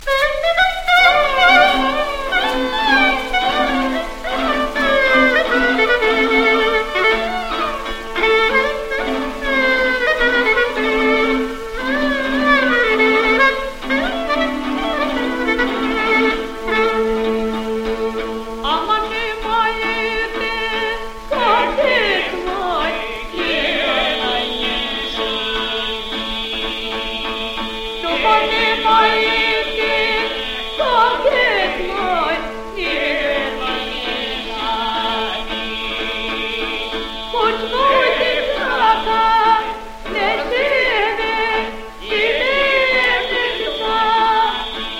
World　USA　12inchレコード　33rpm　Mono